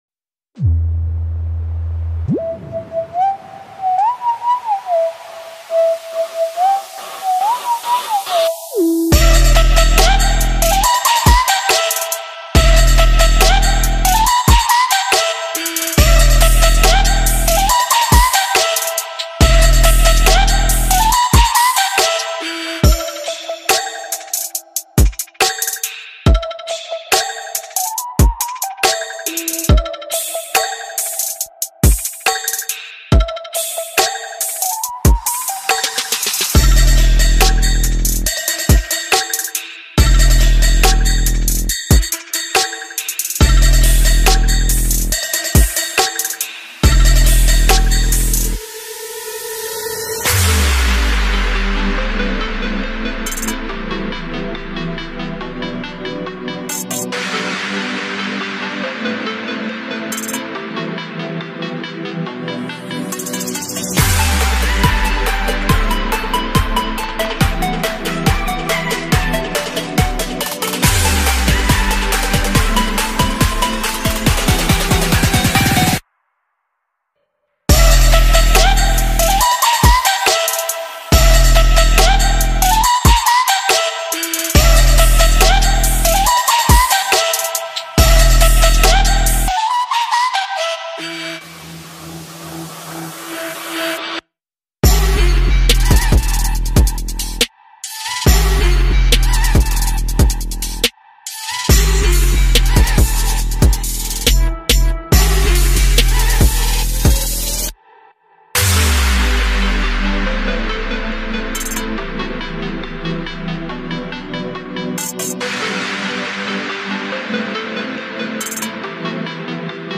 نسخه بی کلام